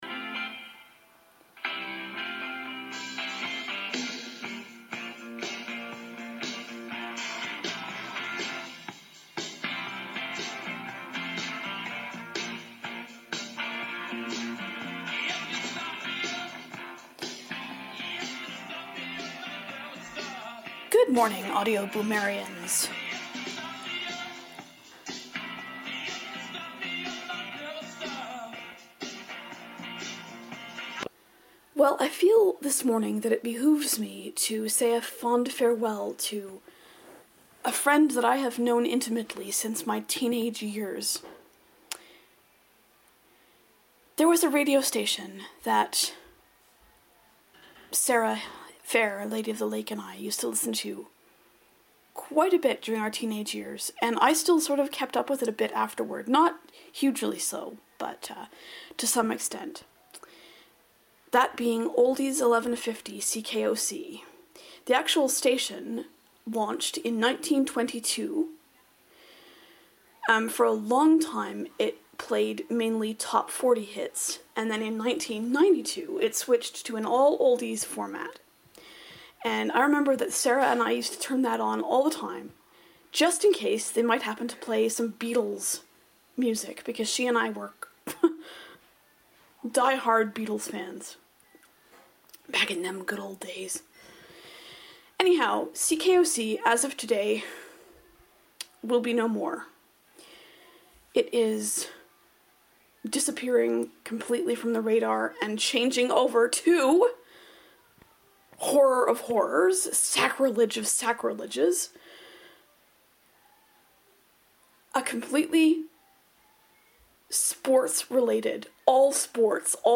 A radio station of which I used to be quite fond is disappearing from the airwaves as of ten o'clock Eastern this morning. In this Boo I deliver an adieu, and also express my complete and utter displeasure at the format it's switching to. Pardon the weirdness; I was in a totally melodramatic mood this morning.